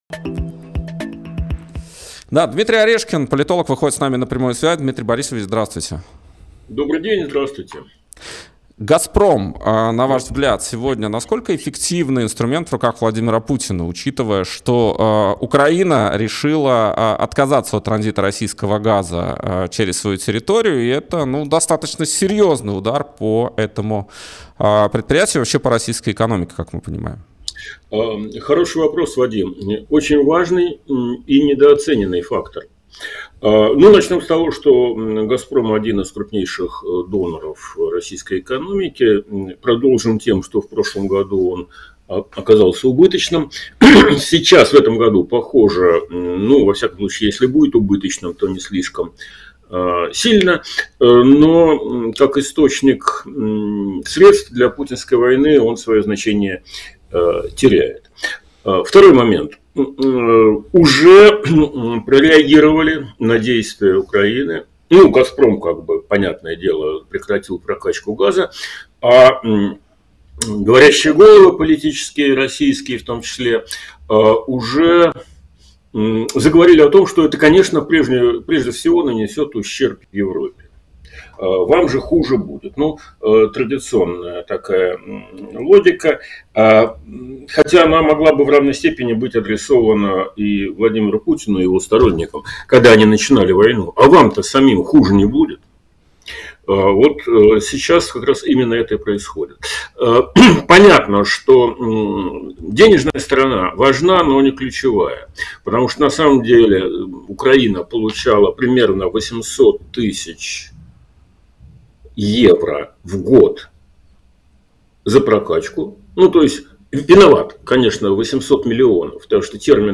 Интервью на канале «И грянул Грэм»